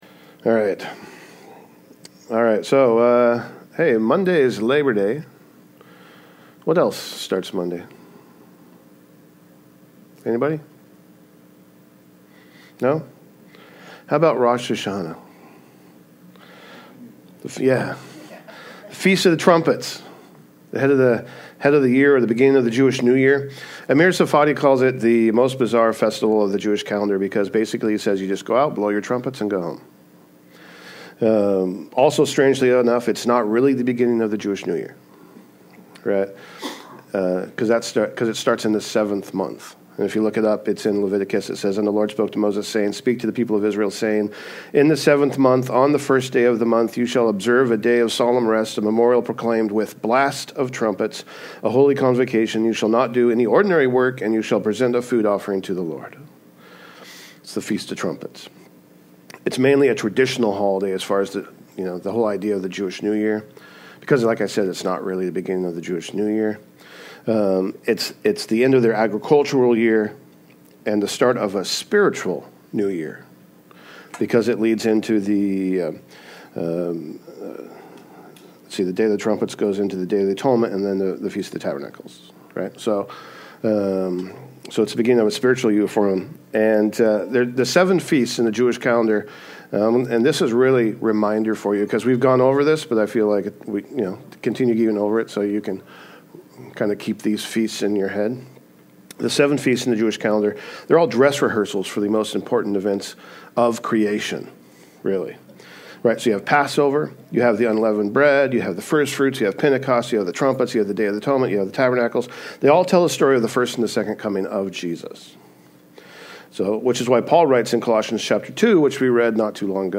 Sermons | Calvary Chapel Snohomish | Snohomish, Wa